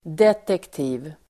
Uttal: [detekt'i:v]